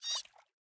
Minecraft Version Minecraft Version snapshot Latest Release | Latest Snapshot snapshot / assets / minecraft / sounds / mob / axolotl / idle_air5.ogg Compare With Compare With Latest Release | Latest Snapshot
idle_air5.ogg